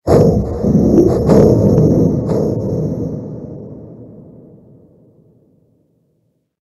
Cri de Miasmax Gigamax dans Pokémon HOME.
Cri_0569_Gigamax_HOME.ogg